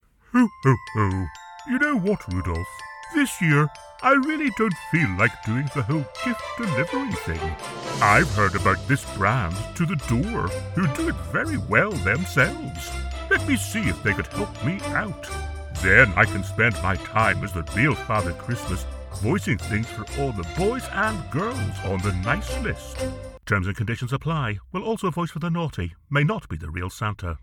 Male
Storytelling ,Baritone , Masculine , Versatile and Thoughtful . Commercial to Corporate , Conversational to Announcer . I have a deep, versatile, powerful voice, My voice can be thoughtful , authoritative and animated . Confident and able to deliver with energy , humorous upbeat and Distinctive . Animated
Radio Commercials